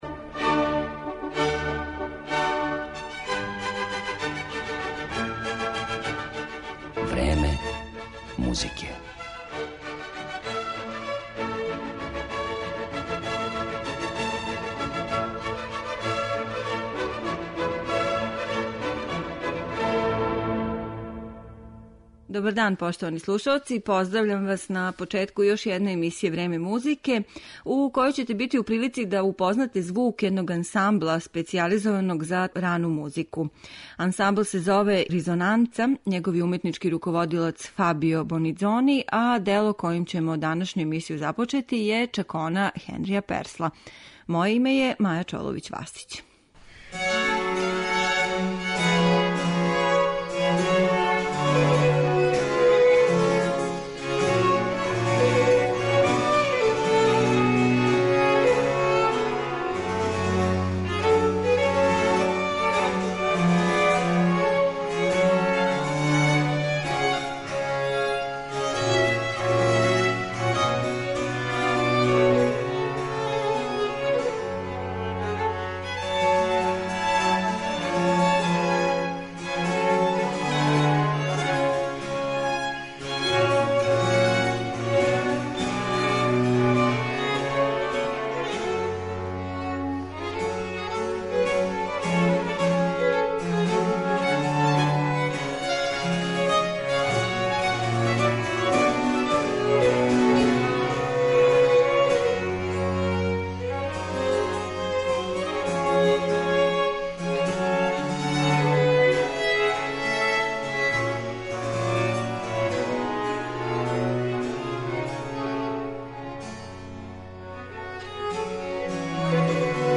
Италијански ансамбл La Risonanza основан је 1995. године и специјализован за извођење ране музике на оригиналним инструментима. Флексибилан састав који се прилагођава изабраном репертоару осваја публику и критичаре специфичном сензуалношћу, шармом и свежином на снимцима на којима су забележена инструментална и вокално-инструментална дела барокних мајстора.